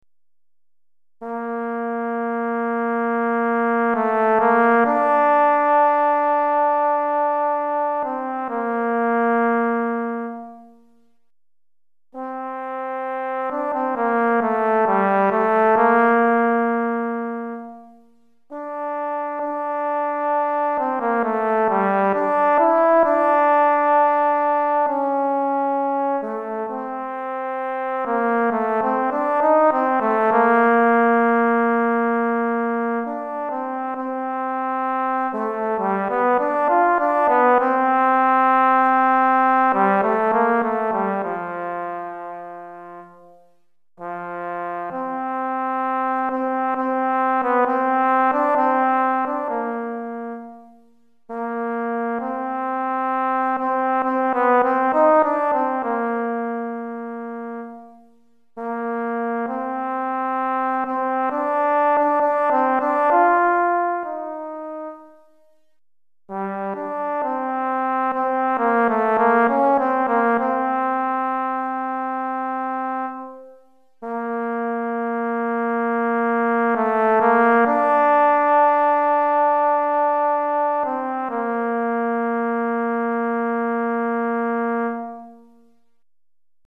Trombone Solo